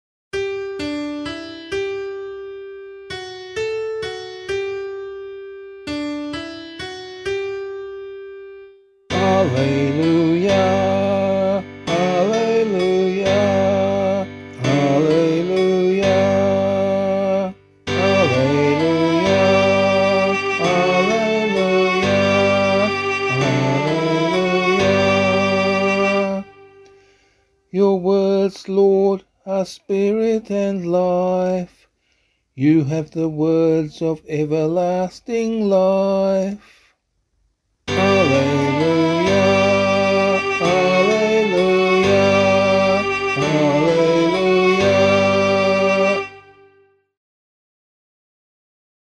Gospel Acclamation for Australian Catholic liturgy.
055 Ordinary Time 21 Gospel B [LiturgyShare F - Oz] - vocal.mp3